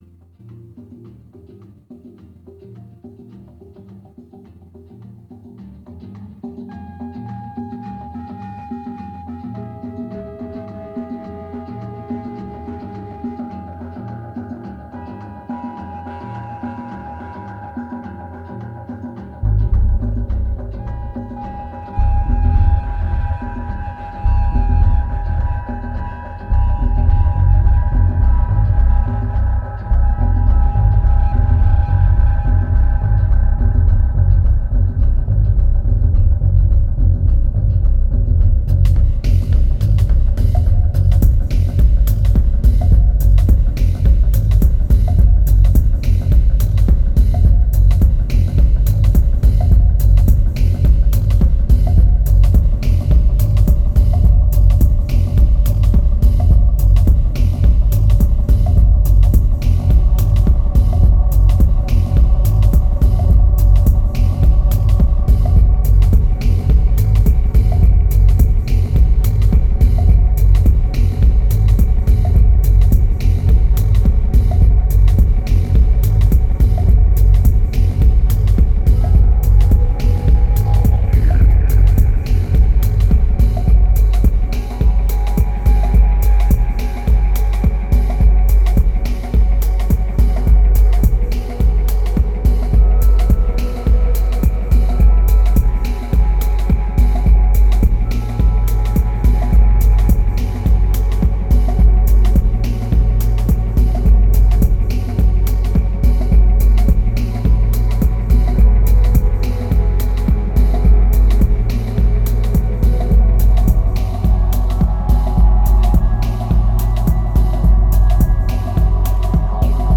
2429📈 - -23%🤔 - 106BPM🔊 - 2010-11-25📅 - -246🌟